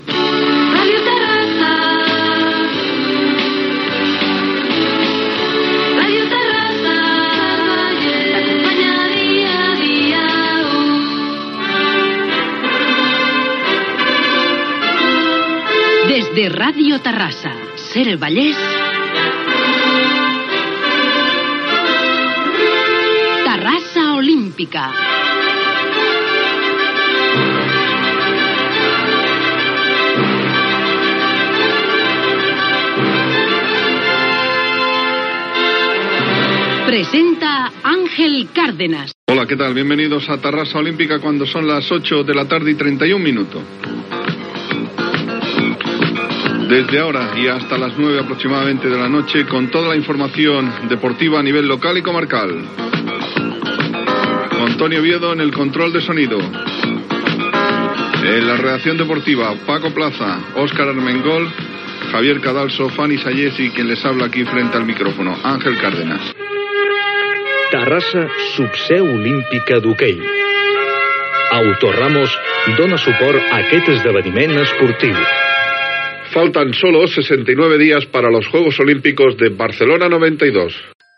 Indicatiu de l'emissora, careta del programa, hora, equip, publicitat, Terrassa serà la seu d'hoquei sobre herba
Esportiu